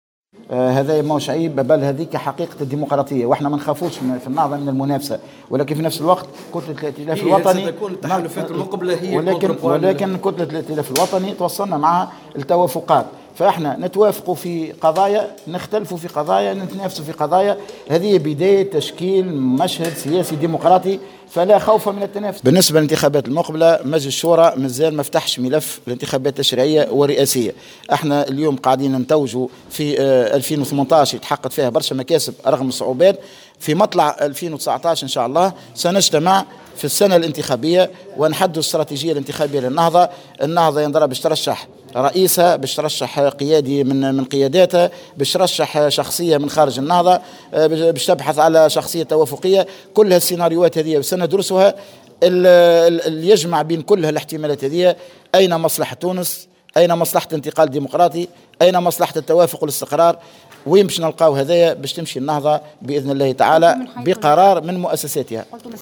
وأعلن الهاروني، في تصريح لمراسلة الجوهرة أف أم، على هامش انعقاد مجلس شورى حركة النهضة، اليوم السبت في الحمامات، أن النهضة ستكون معنية بصفة مباشرة برئاسيات 2019، مضيفا ان الحركة طلبت من الشاهد توضيح مشروعه السياسي لتتضح الصورة بالنسبة للحركة على ضوء ما سيعلنه هذا الأخير، حيث ستحدد استراتيجياتها للانتخابات الرئاسية مطلع السنة القادمة.